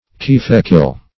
Search Result for " kiefekil" : The Collaborative International Dictionary of English v.0.48: Kiefekil \Kie"fe*kil\, n. [Per. keff foam, scum + gil clay, mud.]